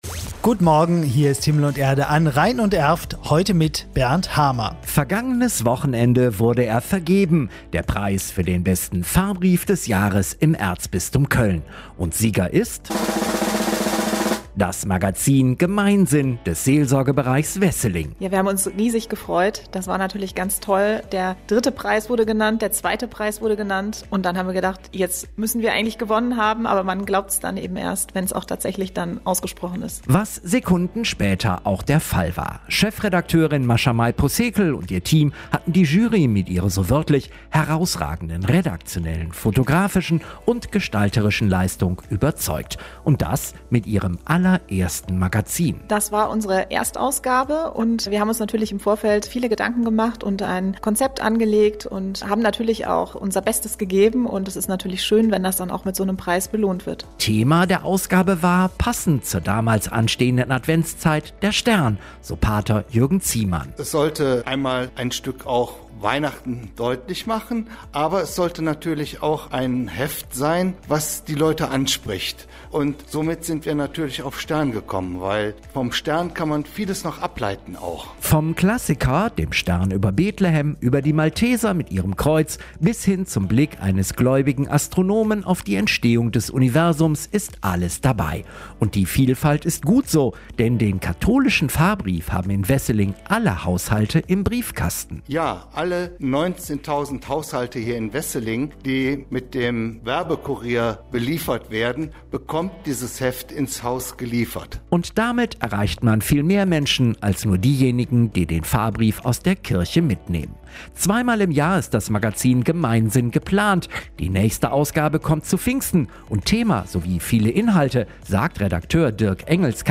Interview mit Radio-Rhein-Erft
Interview_pfarrbrief_des_Jahres.mp3